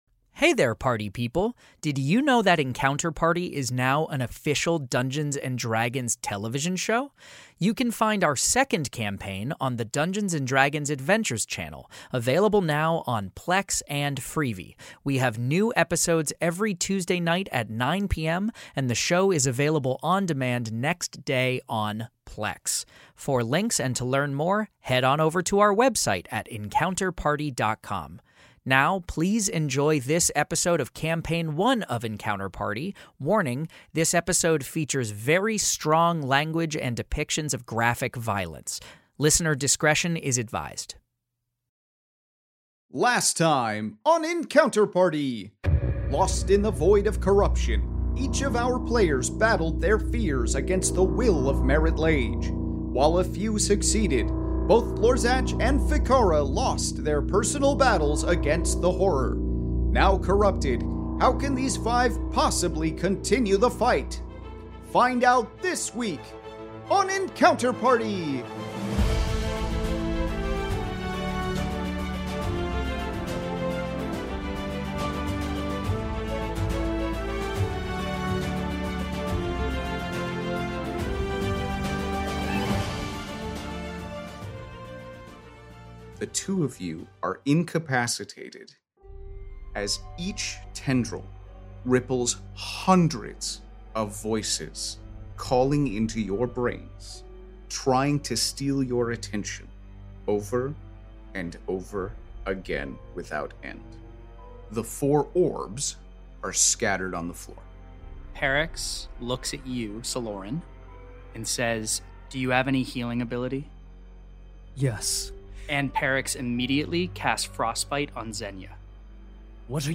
Fantasy Mystery Audio Adventure
five actors and comedians